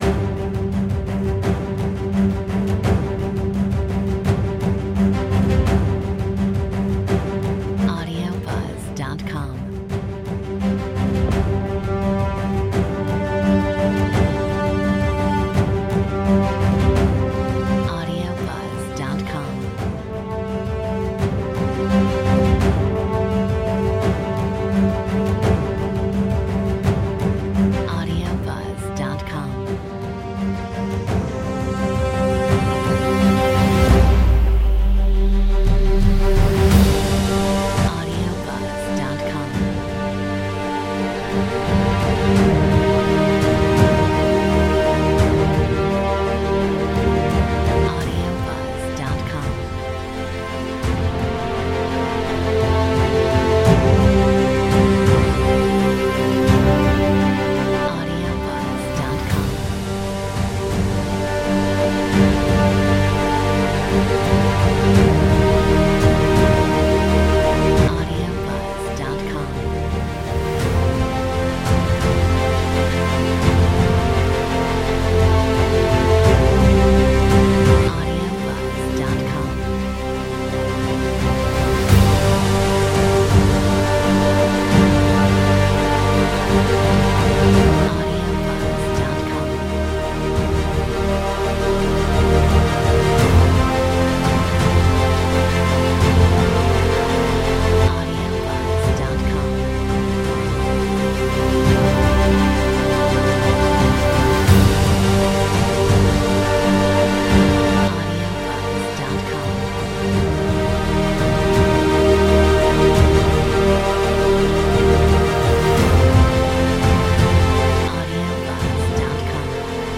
Metronome 85